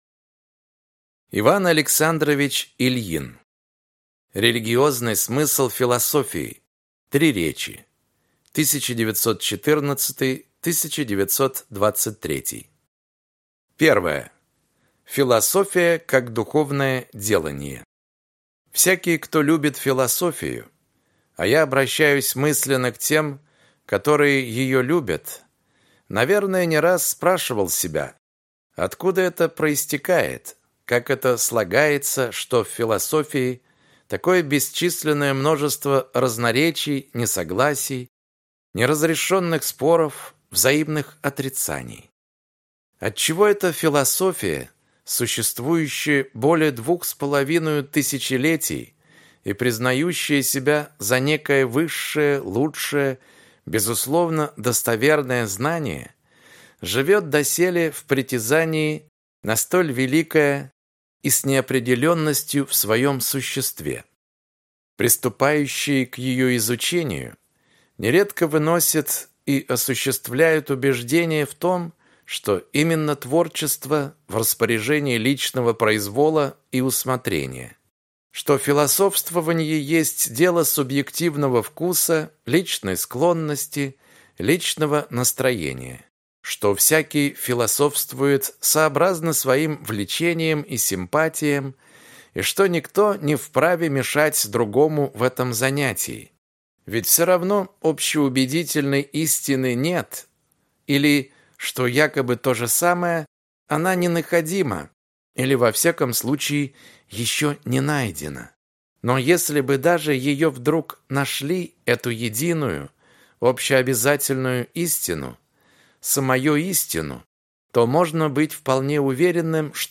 Аудиокнига Религиозный смысл философии | Библиотека аудиокниг